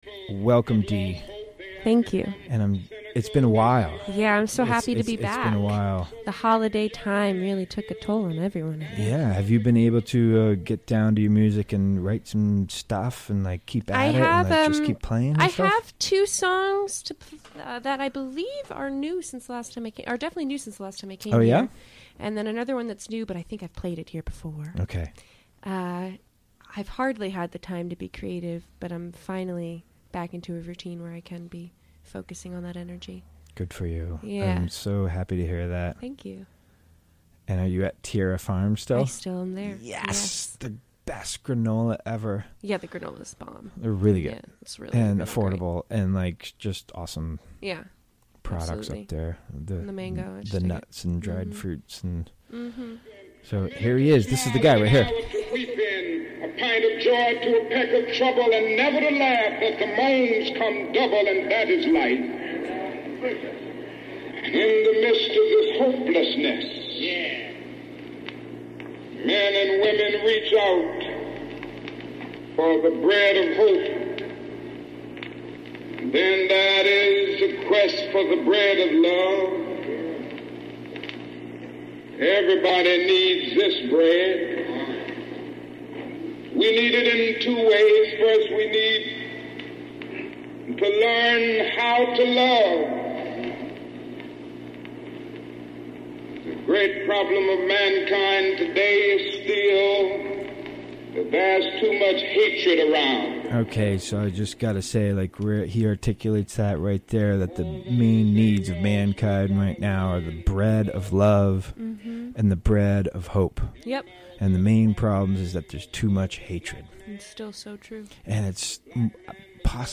Recorded during the WGXC Afternoon Show Monday, January 15, 2018.